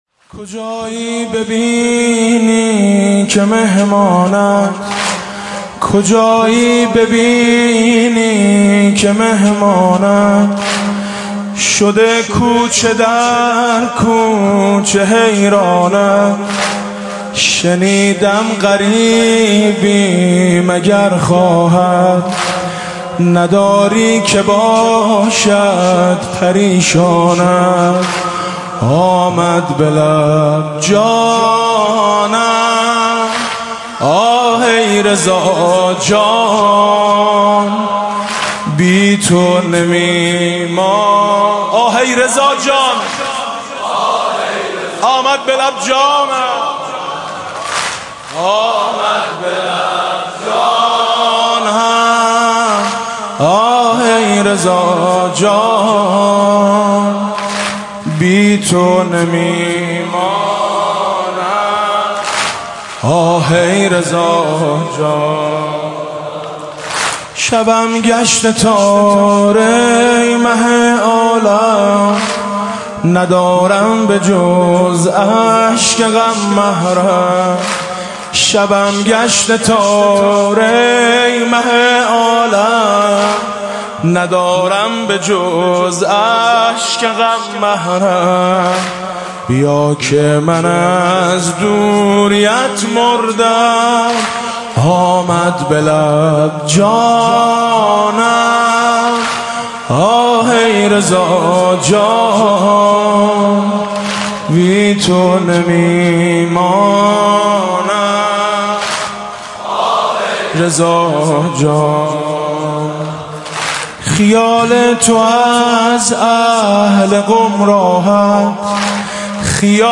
گلچین مداحی های شهادت حضرت معصومه(س)